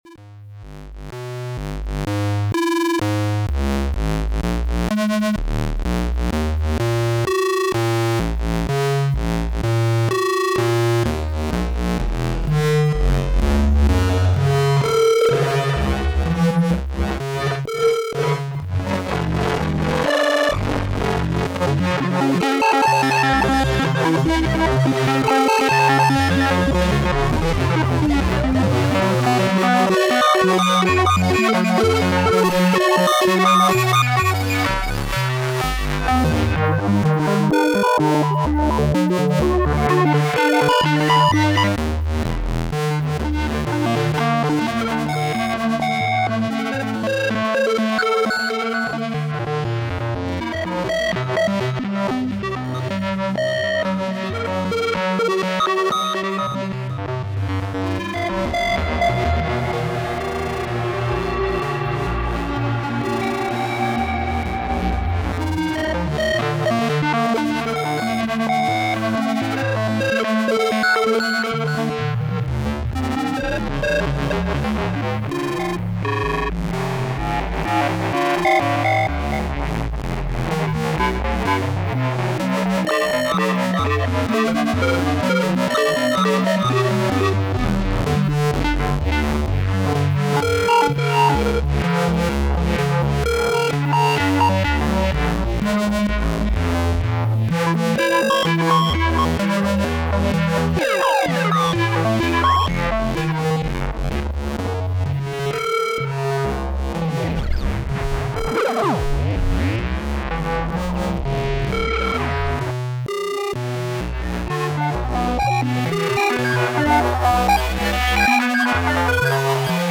Strega into Rev C.
0-Ctrl, controlling Strega with 0-Coast patched in, Ring Modded all the way.
It’s mostly dry, but on a couple spots I added CXM 1978 for a bit of context.
*Secret settings engaged on the fly, for extra roughness.